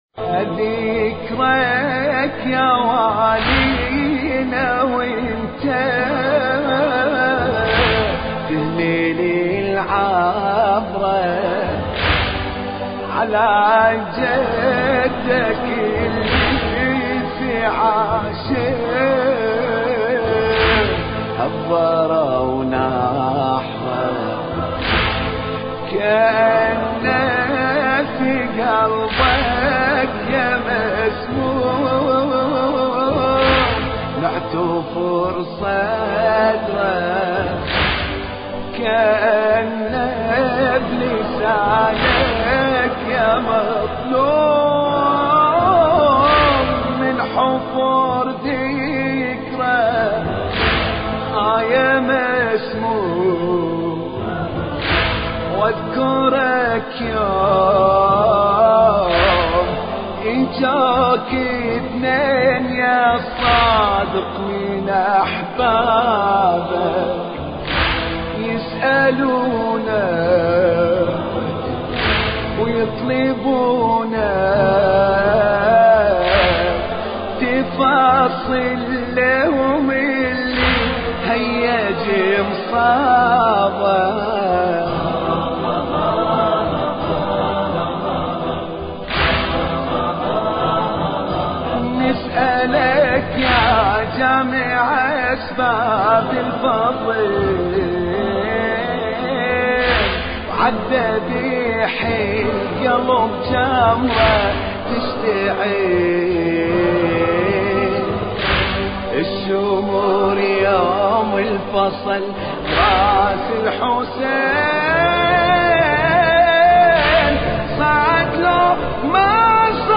مراثي الامام الصادق (ع)